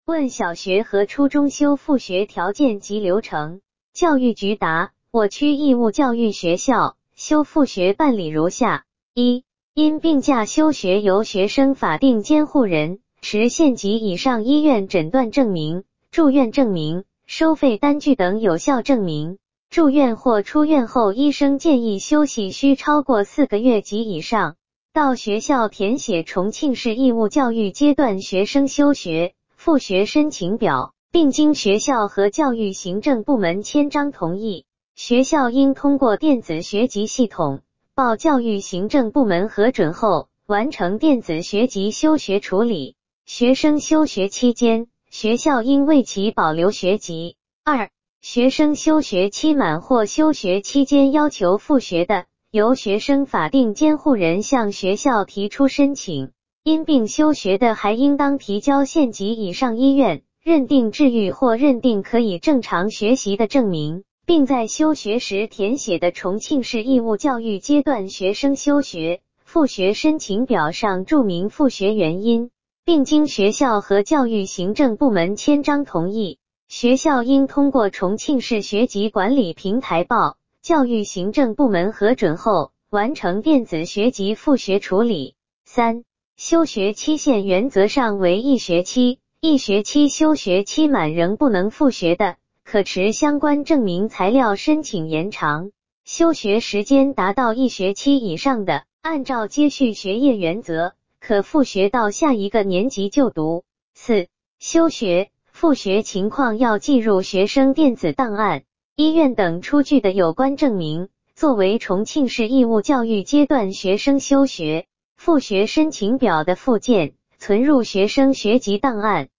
语音播报
语音合成中，请耐心等待...